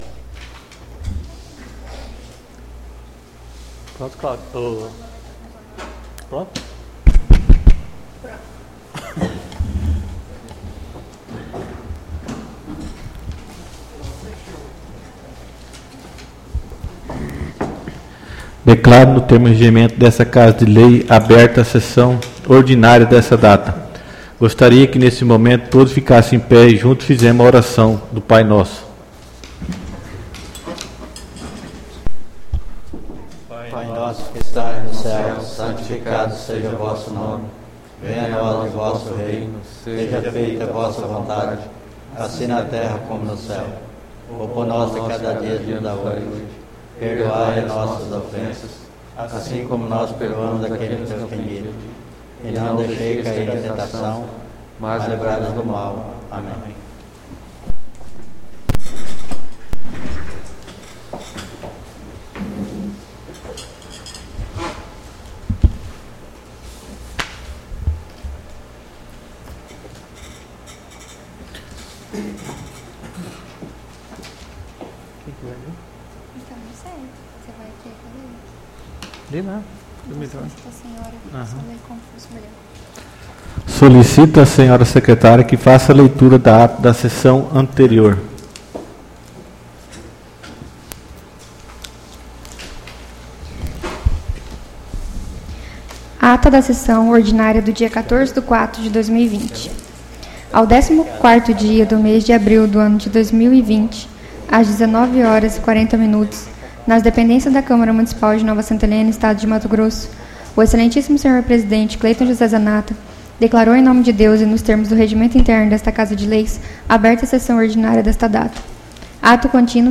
SESSÃO ORDINÁRIA DO DIA 28/04/2020